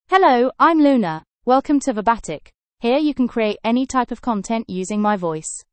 FemaleEnglish (United Kingdom)
Luna is a female AI voice for English (United Kingdom).
Voice sample
Female
Luna delivers clear pronunciation with authentic United Kingdom English intonation, making your content sound professionally produced.